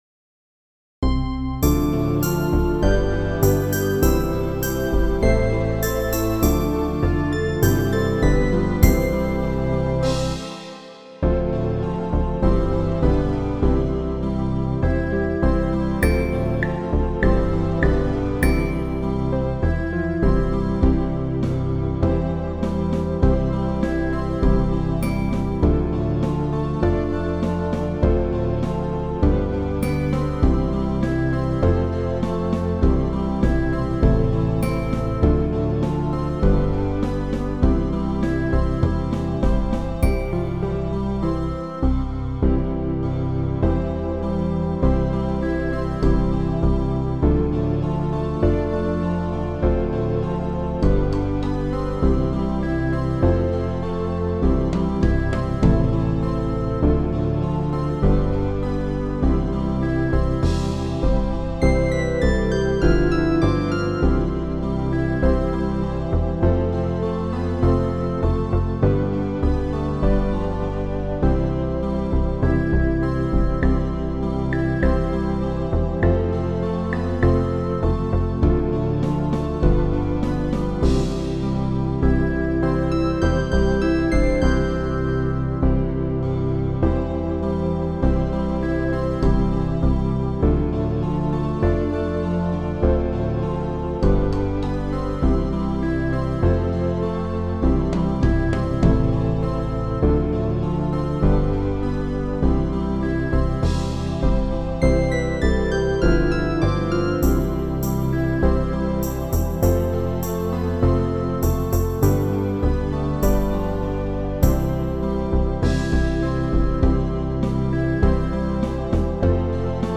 MP3 background track